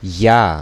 Ääntäminen
IPA: [joː]